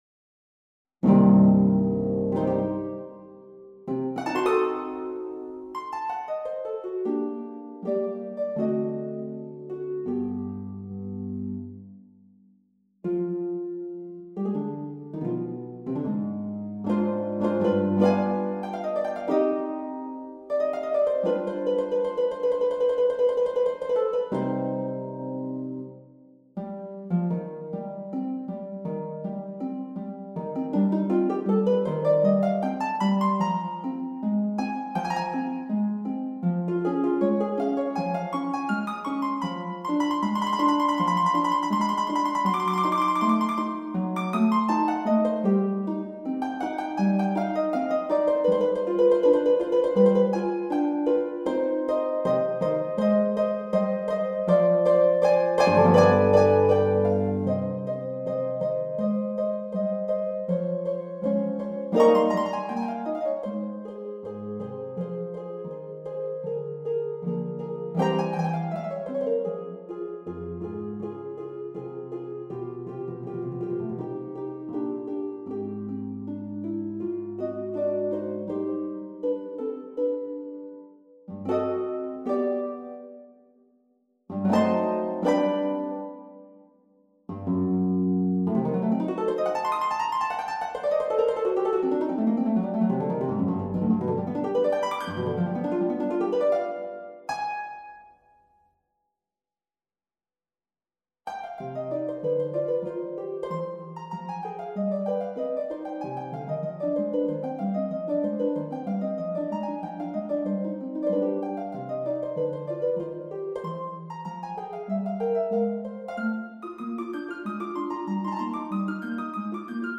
Classical Spohr, Louis Fantasia for Harp, Op.35 Harp version
Harp  (View more Advanced Harp Music)
Classical (View more Classical Harp Music)